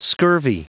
Prononciation du mot scurvy en anglais (fichier audio)
Prononciation du mot : scurvy